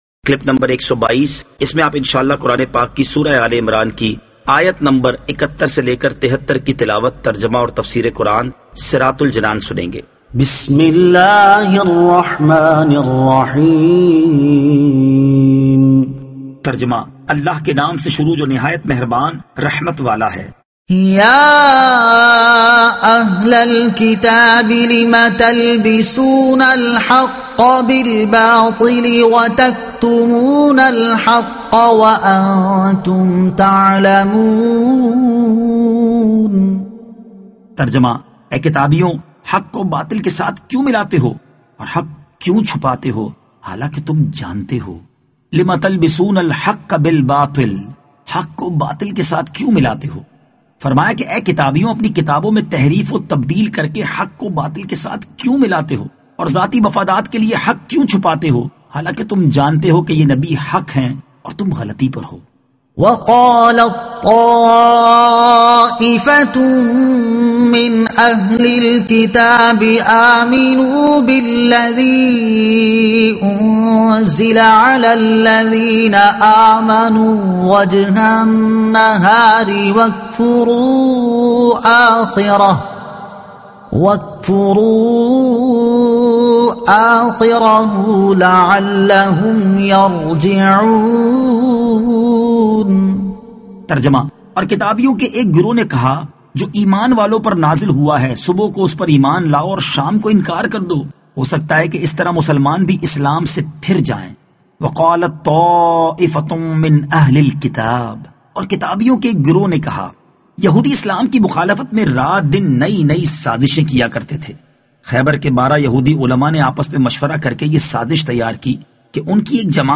Surah Aal-e-Imran Ayat 71 To 73 Tilawat , Tarjuma , Tafseer